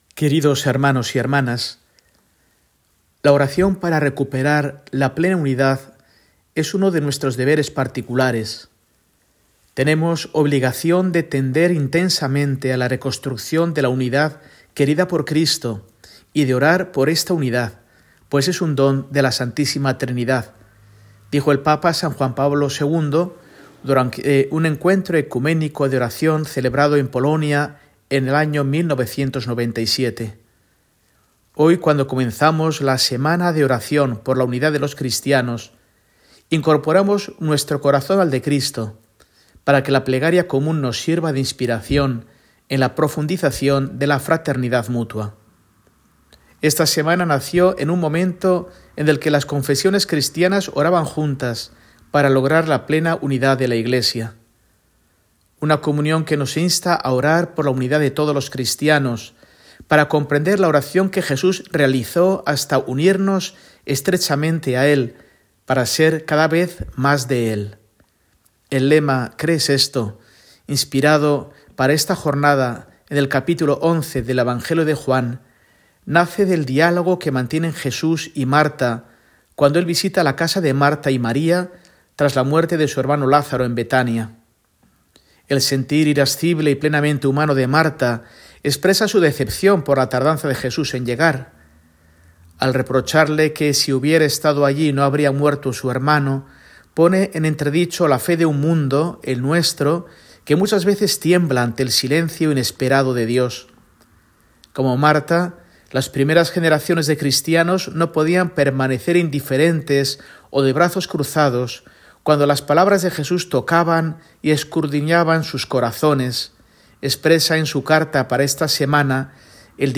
Carta semanal de Mons. Mario Iceta Gavicagogeascoa, arzobispo de Burgos, para el domingo, 19 de enero de 2025, II del Tiempo Ordinario